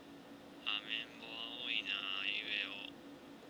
スピーカーもパワーがありません
(少し距離があるため弱めです)
Pimaxスピーカー